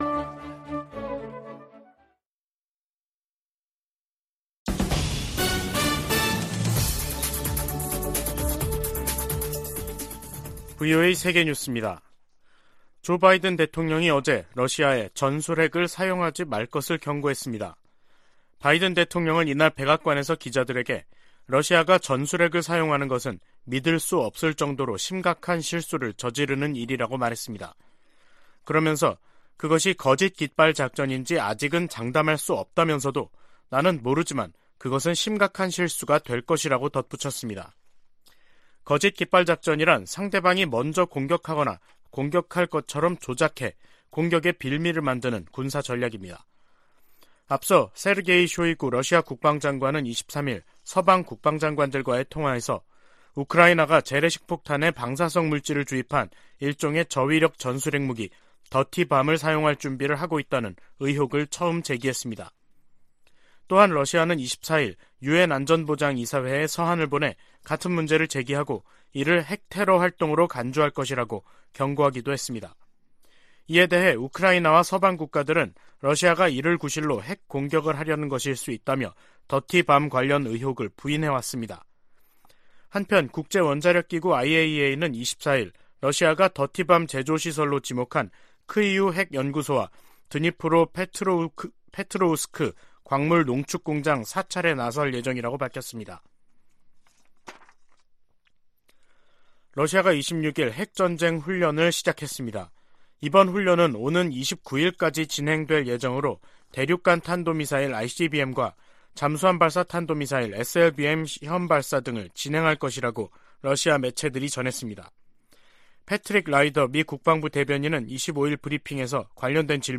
VOA 한국어 간판 뉴스 프로그램 '뉴스 투데이', 2022년 10월 26일 2부 방송입니다. 미국·한국·일본의 외교차관이 26일 도쿄에서 협의회를 열고 핵실험 등 북한의 추가 도발 중단을 강력히 촉구했습니다. 미 국무부는 북한이 7차 핵실험을 강행할 경우 대가를 치를 것이라고 경고한 사실을 다시 강조했습니다. 미 국방부는 한반도 전술핵 재배치와 관련한 질문에 강력한 억지력 보장을 위해 한국·일본 등 동맹과 긴밀히 협력할 것이라고 밝혔습니다.